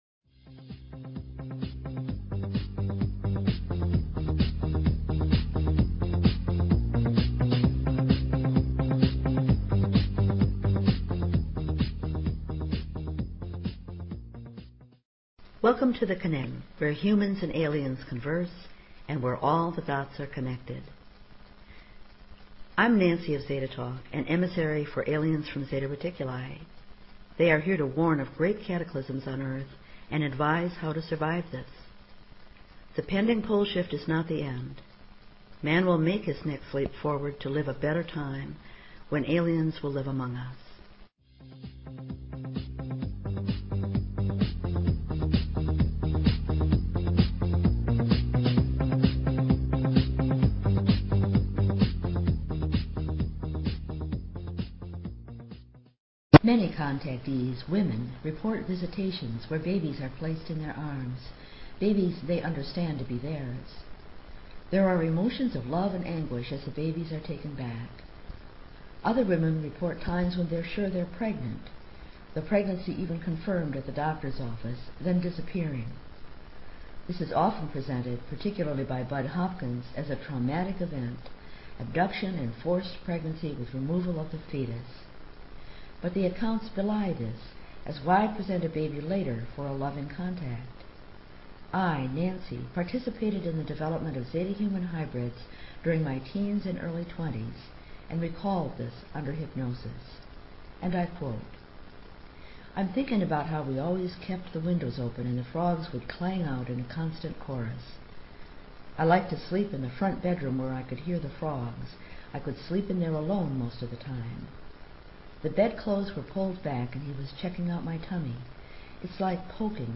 Courtesy of BBS Radio